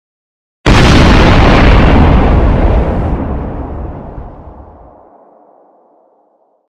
Big Explosion.mp3